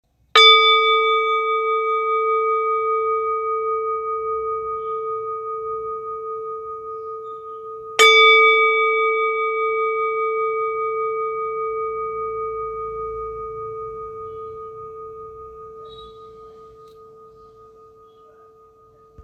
Nepali Singing Bowl with Double Dorje Carved Inside , Select Accessories
Material Seven Metal Bronze